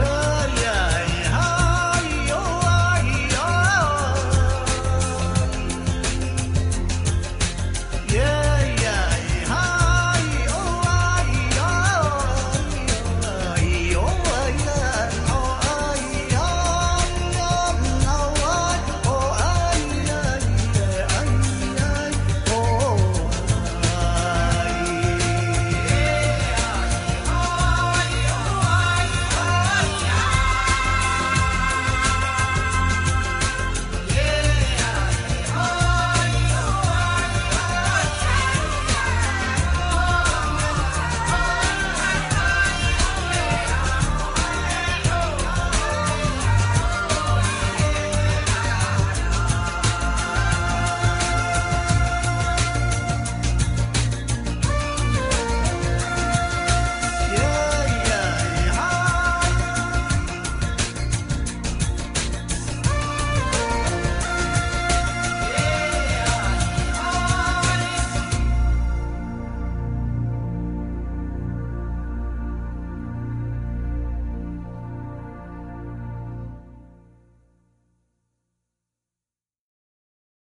黨歌